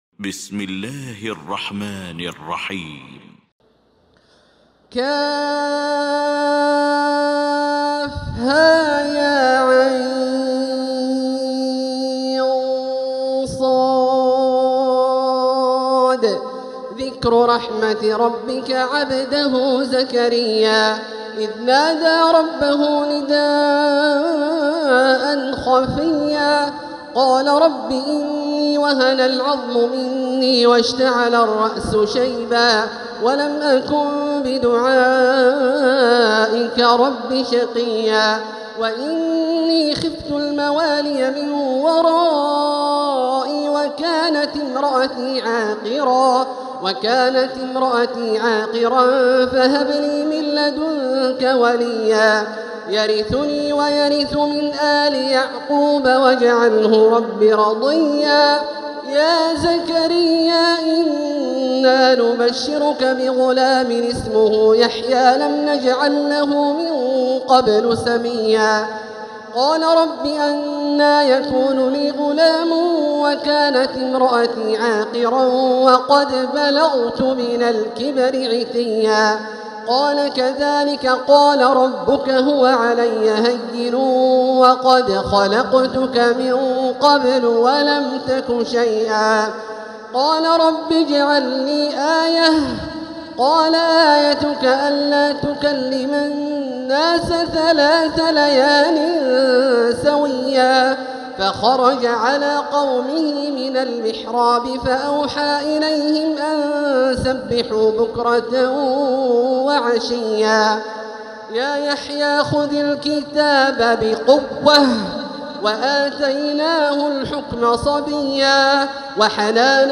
المكان: المسجد الحرام الشيخ: معالي الشيخ أ.د. بندر بليلة معالي الشيخ أ.د. بندر بليلة فضيلة الشيخ عبدالله الجهني مريم